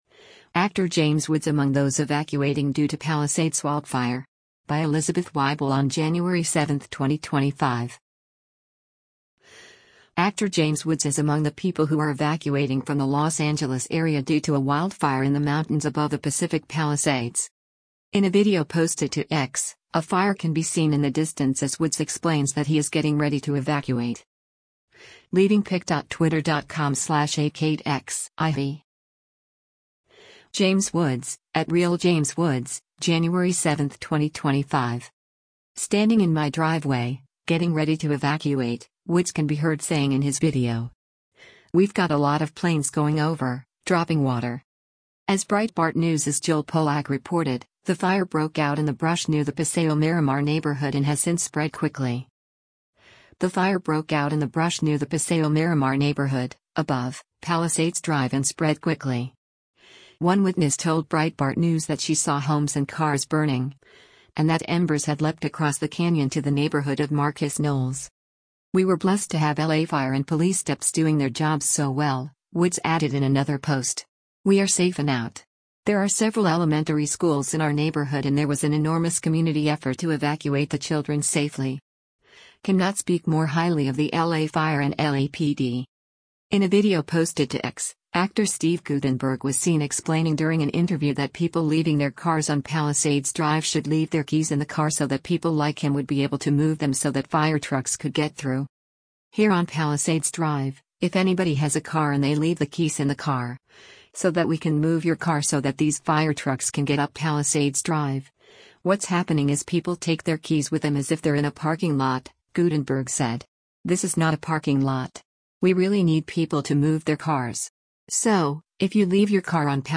In a video posted to X, a fire can be seen in the distance as Woods explains that he is “getting ready to evacuate.”
“Standing in my driveway, getting ready to evacuate,” Woods can be heard saying in his video. “We’ve got a lot of planes going over, dropping water”